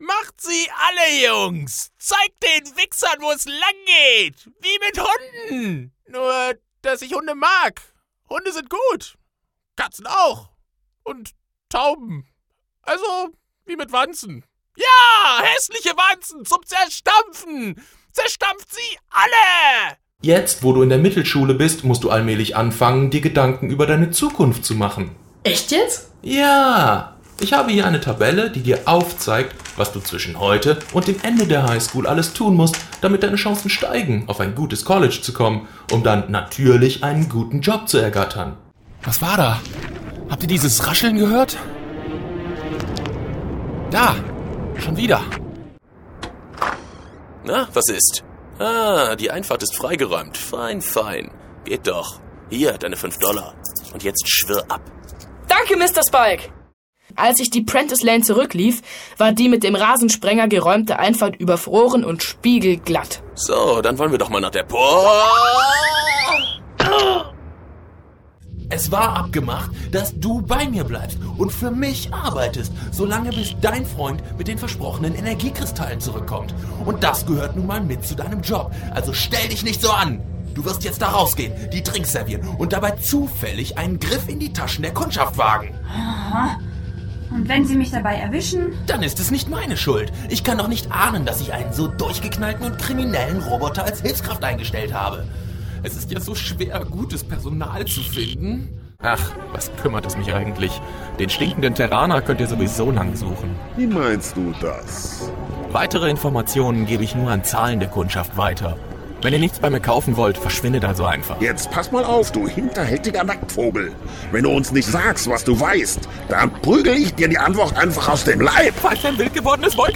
präsent, wandelbar, flexibel, spontan - Eigenes Studio mit ISDN, SourceConnect & Skype
Sprechprobe: Sonstiges (Muttersprache):